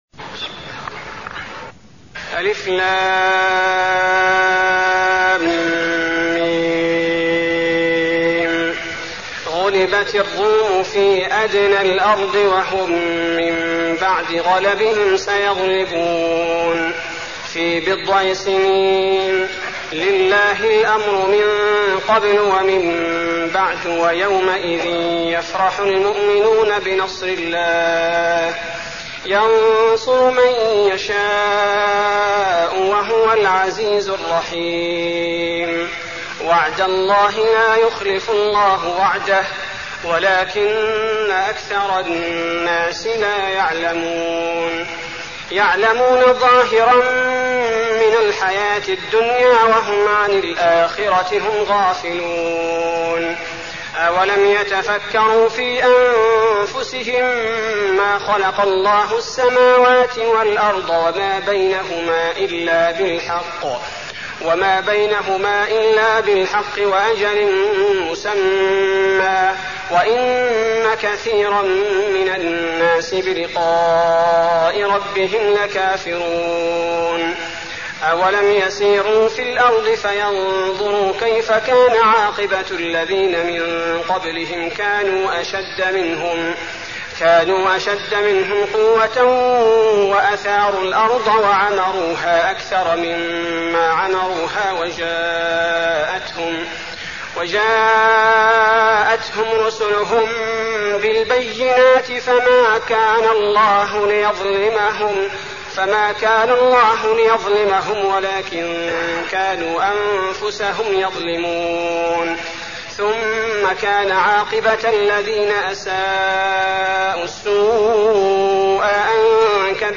المكان: المسجد النبوي الروم